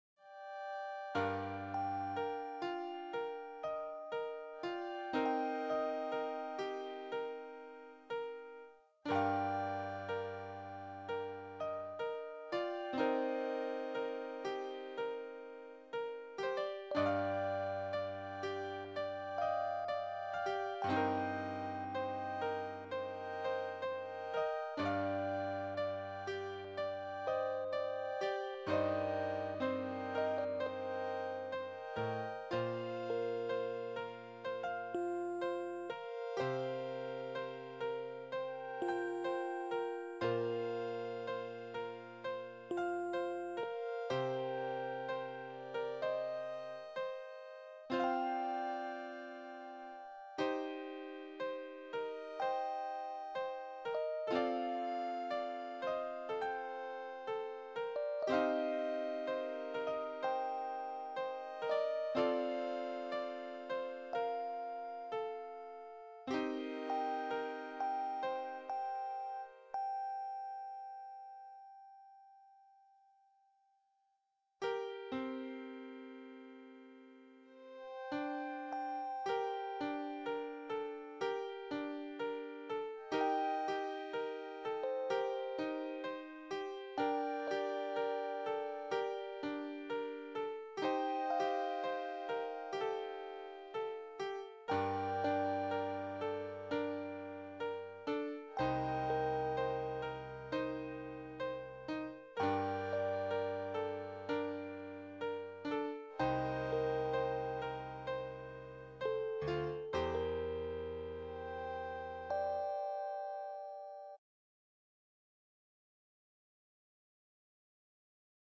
Another Style with Metallic Pad (might be metallic harp)